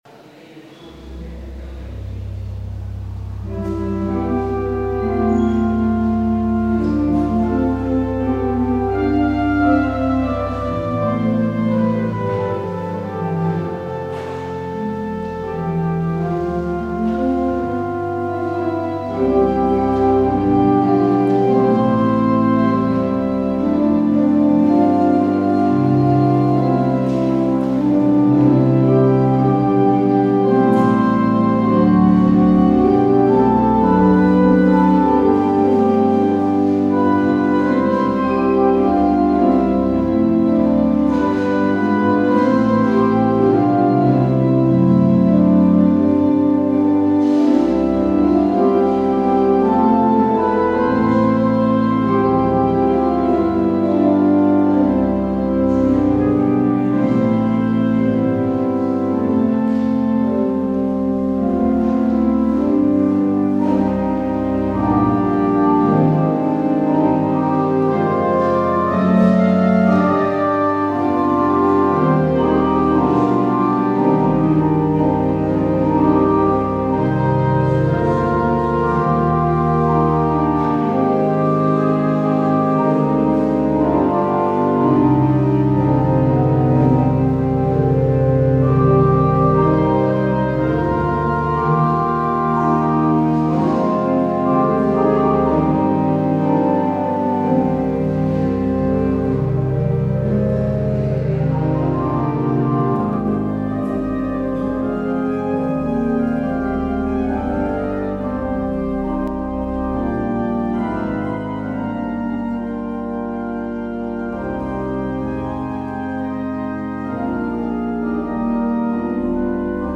Luister deze kerkdienst hier terug:
Het openingslied is: NLB 280: 1, 3, 5 en 7.
Het slotlied is: Zingen: NLB 885: 1 en 2.